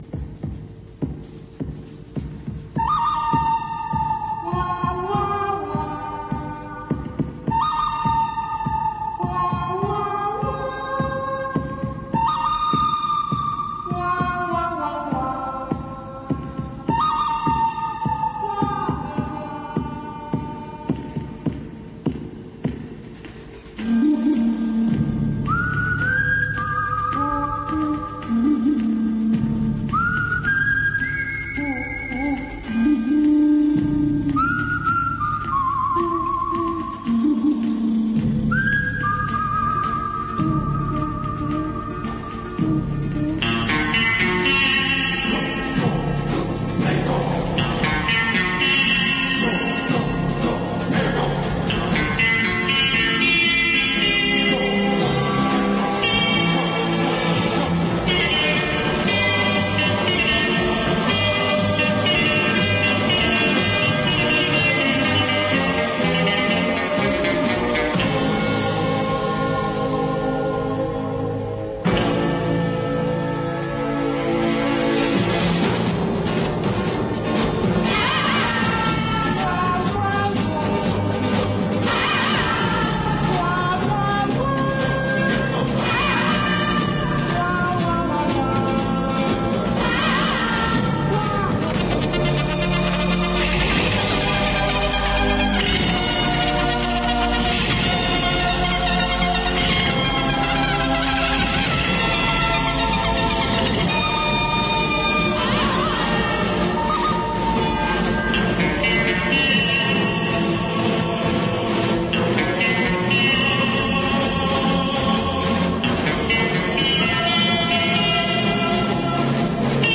Una mica més madurs però encara no jubilats els majares reben la visita de membres de La Tele i de radios estatals que han vingut a la Trobada de la Xarxa de Mitjans Comunitaris realitzada a Barcelona. Companys de Cuac FM d’ A Corunya, Onda Color de Málaga i Radio Pikaza del altiplano granadino ens parlen de les penes i desgràcies d’aquest sector.